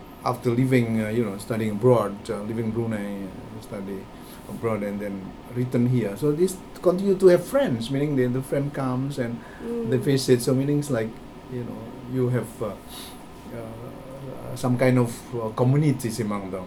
S1 = Taiwanese female S2 = Indonesian male
The lack of voicing on the [v] and also the medial [z] in visit resulted in S1 fearing it as face .